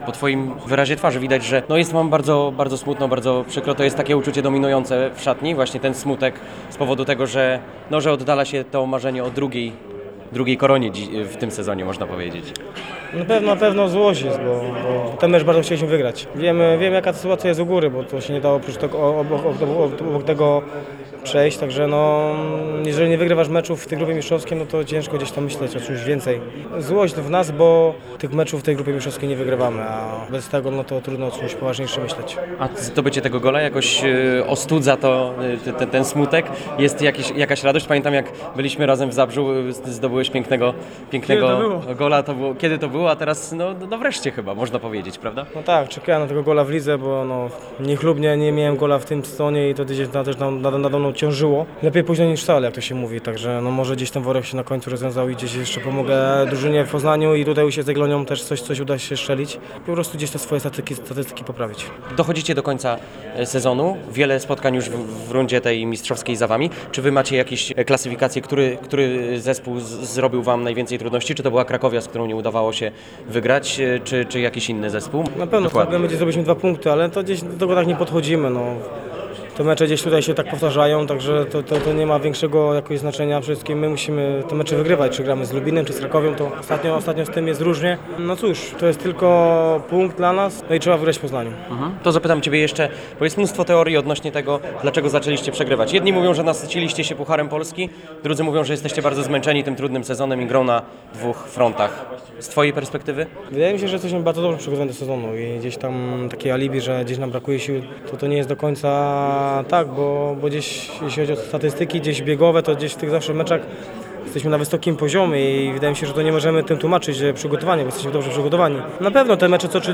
Posłuchaj rozmowy z Michałem Makiem: